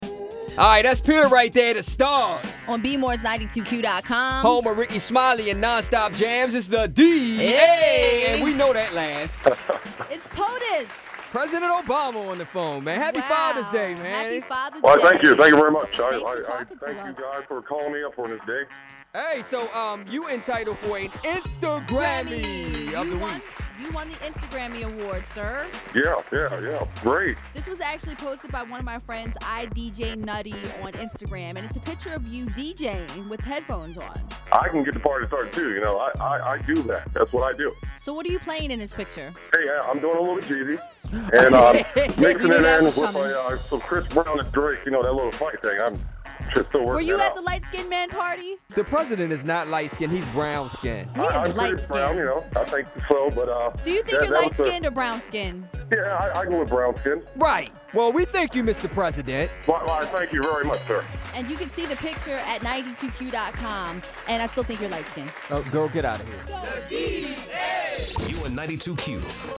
We got a chance to talk to the President and give him his award.